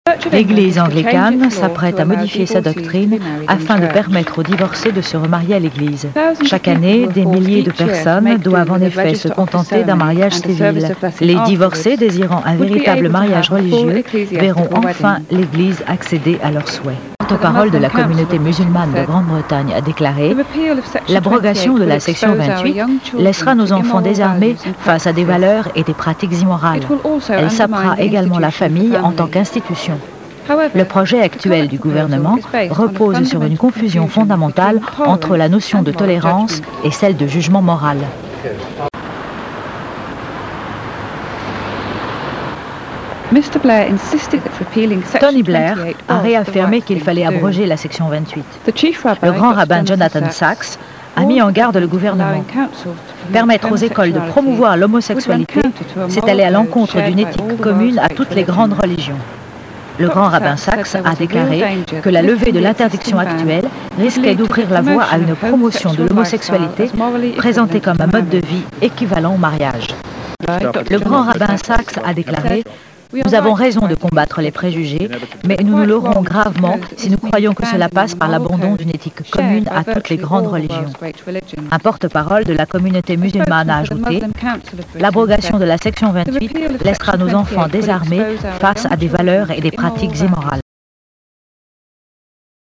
voice over pour France3
Voix off